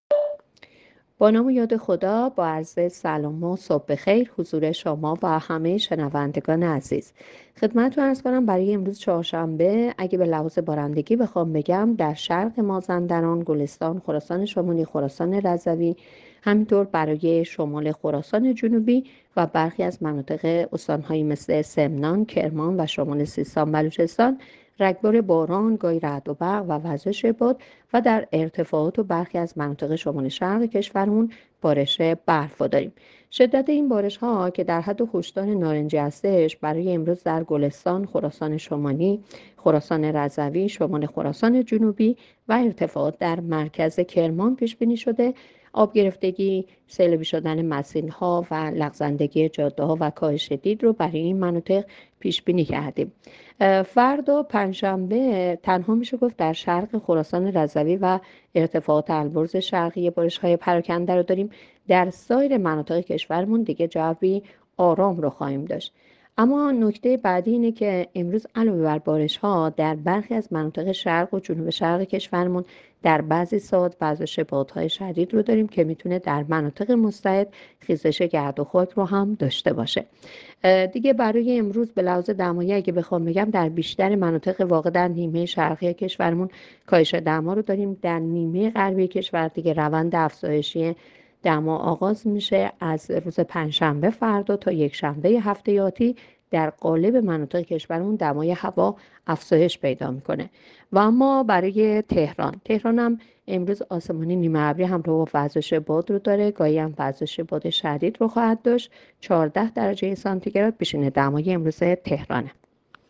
گزارش رادیو اینترنتی پایگاه‌ خبری از آخرین وضعیت آب‌وهوای ۶ فروردین؛